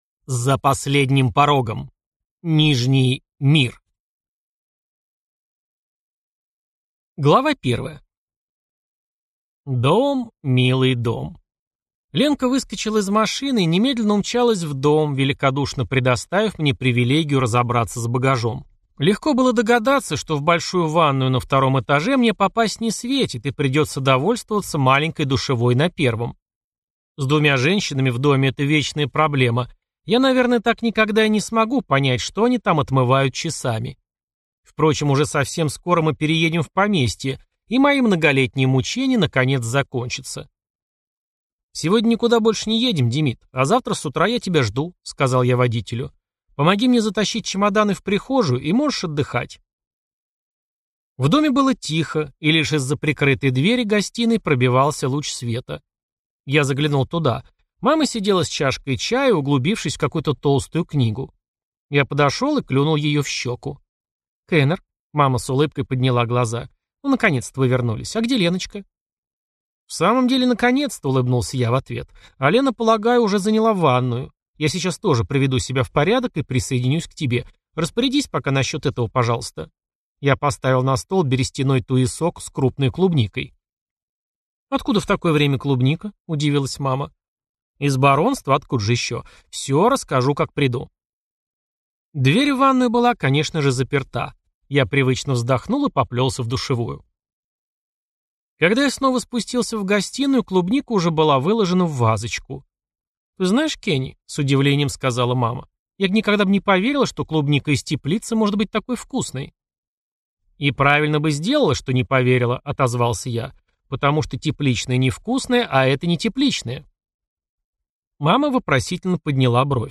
Аудиокнига За последним порогом. Нижний мир | Библиотека аудиокниг